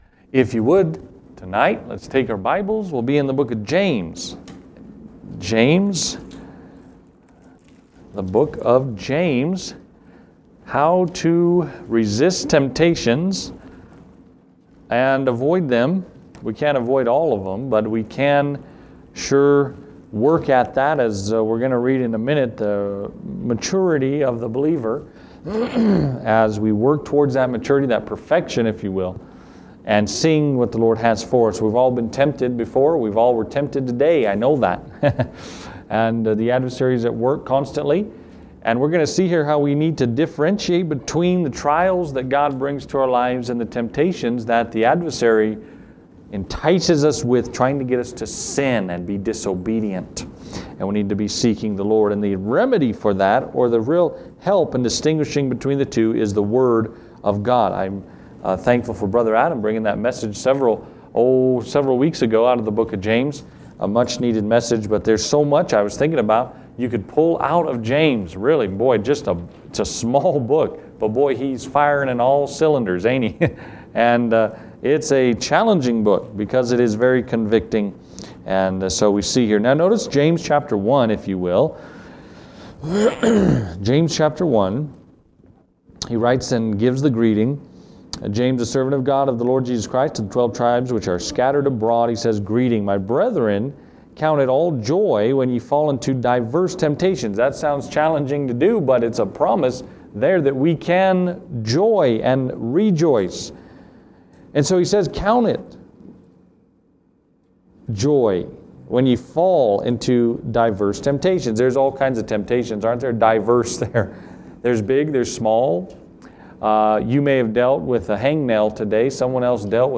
(Higher quality audio is available on the audio only recording)